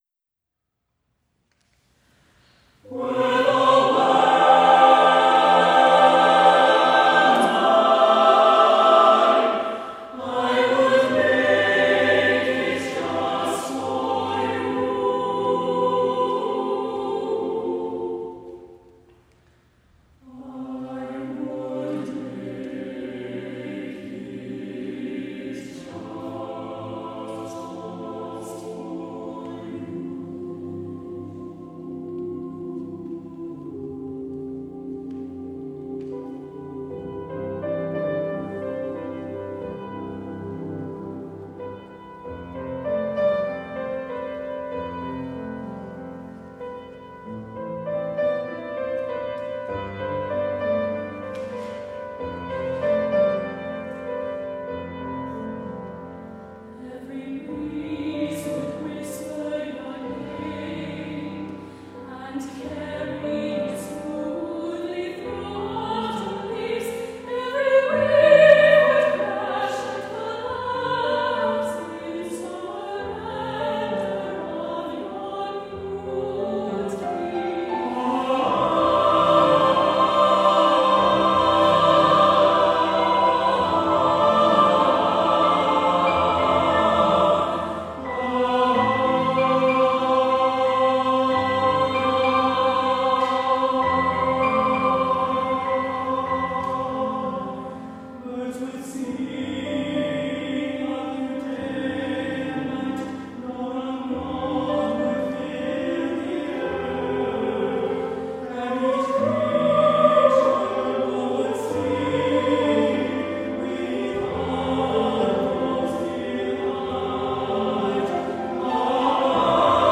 SATB Chorus with divisi & Piano